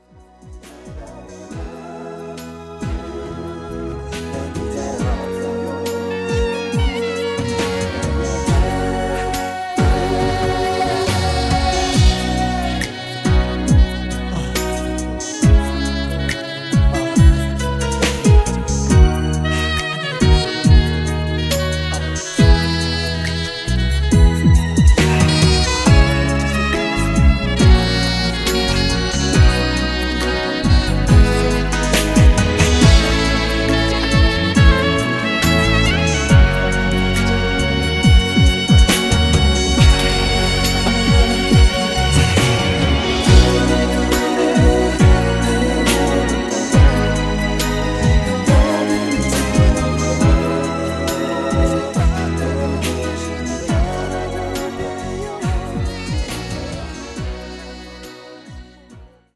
음정 -1키 5:00
장르 가요 구분 Voice MR
보이스 MR은 가이드 보컬이 포함되어 있어 유용합니다.